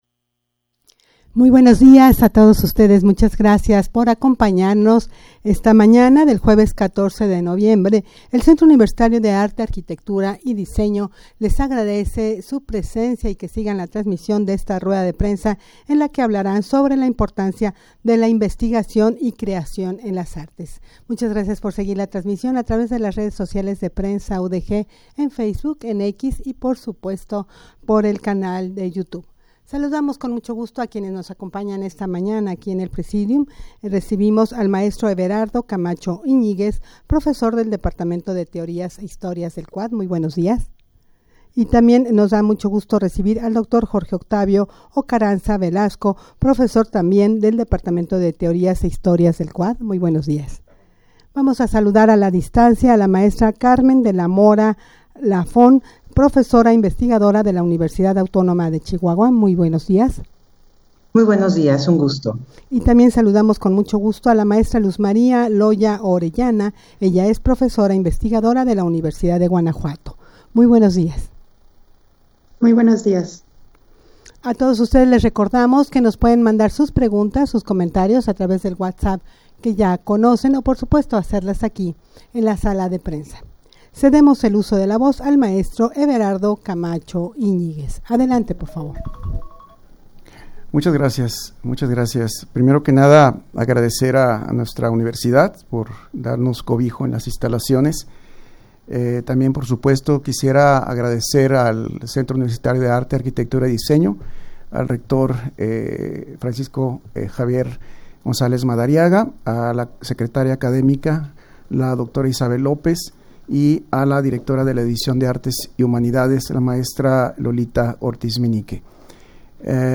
rueda-de-prensa-para-hablar-sobre-la-importancia-de-la-investigacion-y-creacion-en-las-artes.mp3